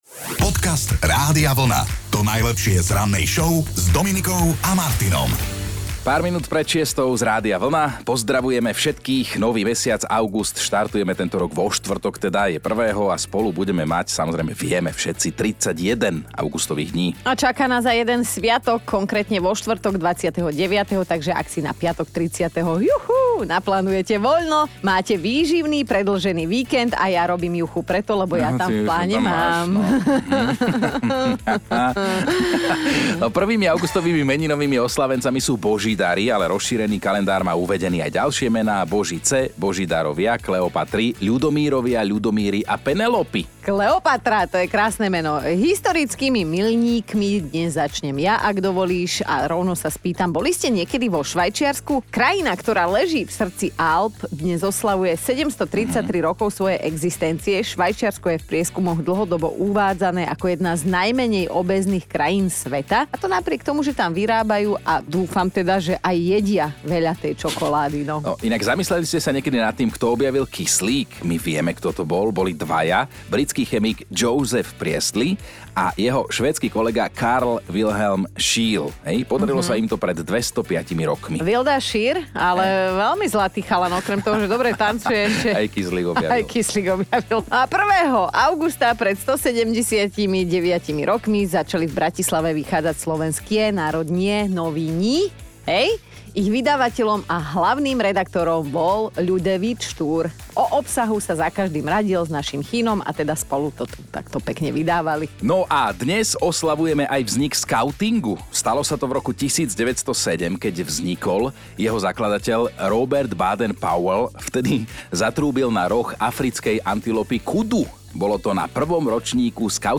Prišli k nám do štúdia, lebo ... ako sa hovorí, niečo sa chystá!